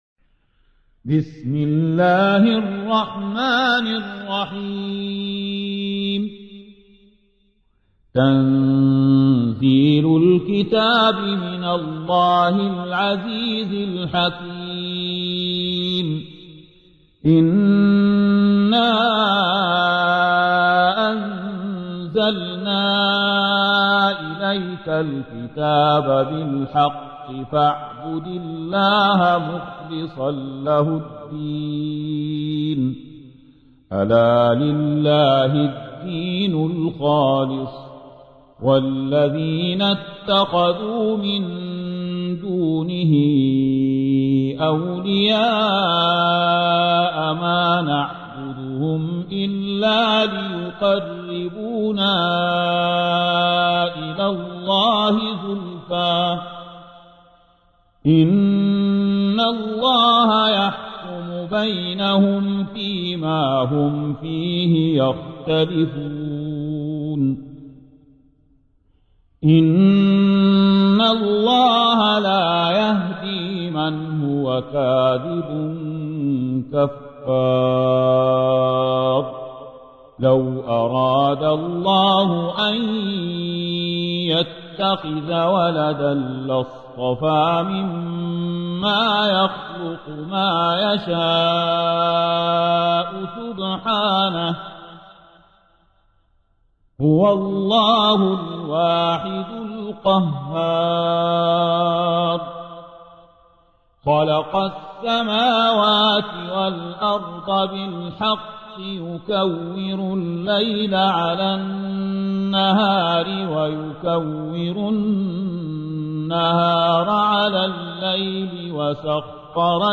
تحميل : 39. سورة الزمر / القارئ زكي داغستاني / القرآن الكريم / موقع يا حسين